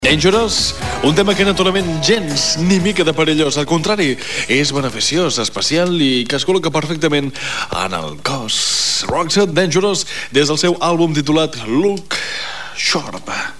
Presentació d'un tema musical
Musical